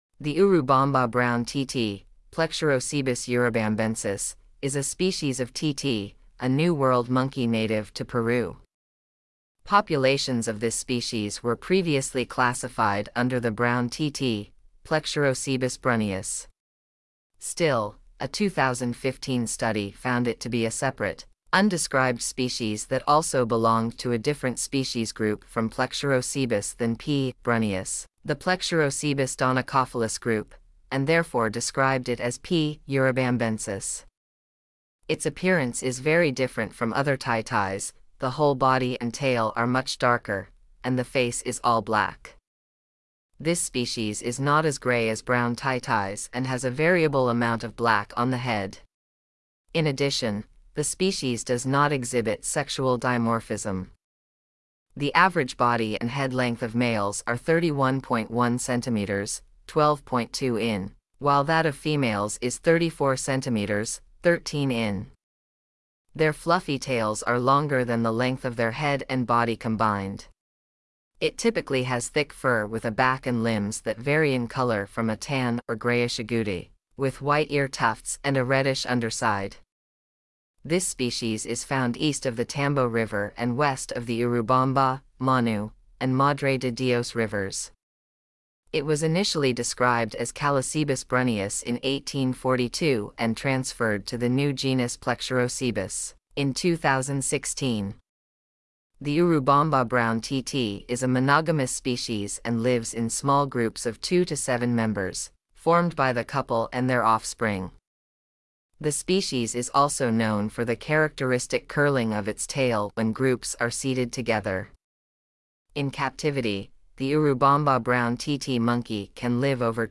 Urubamba Brown Titi
Urubamba-Brown-Titi.mp3